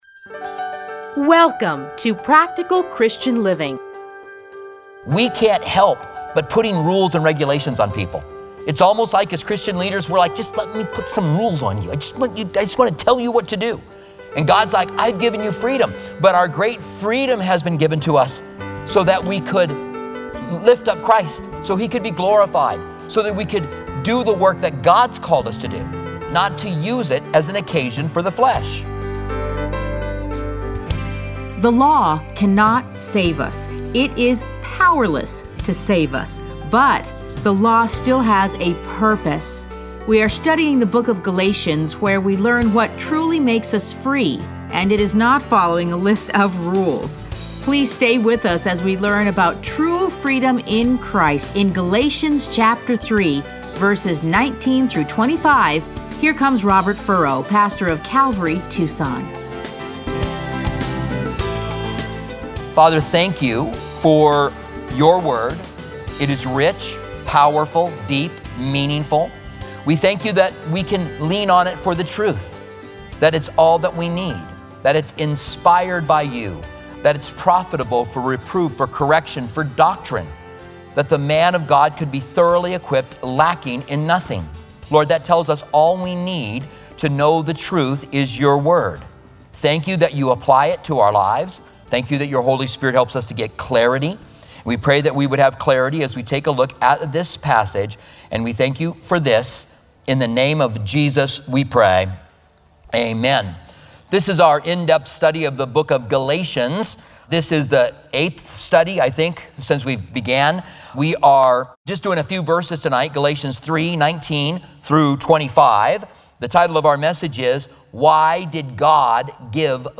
Listen to a teaching from Galatians 3:19-25.